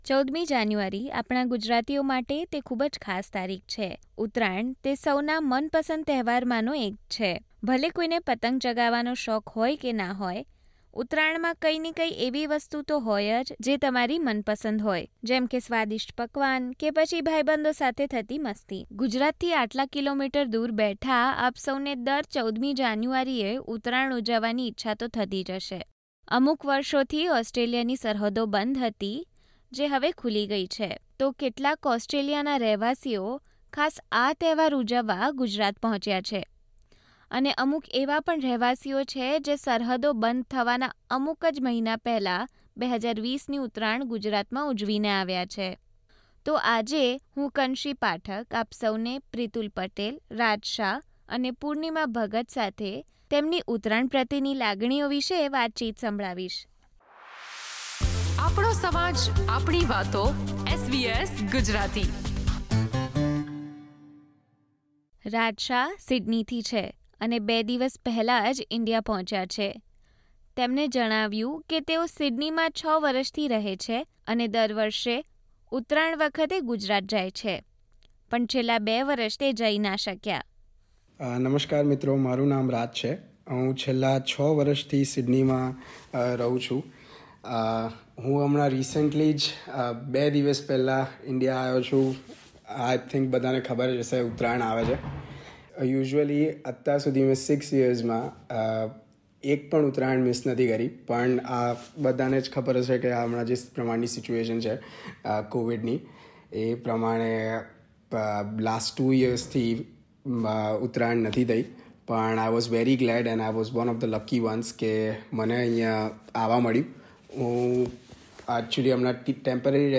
આ તકનો લાભ લઇ ઓસ્ટ્રેલિયામાં રહેતા ગુજરાતી મૂળના લોકો ઉત્તરાયણના તહેવારની ઉજવણી કરવા ભારત ગયા છે. તેમણે વર્ષો બાદ ભારતમાં ઉત્તરાયણનો તહેવાર મનાવવા અંગે SBS Gujarati સાથે વાત કરી હતી.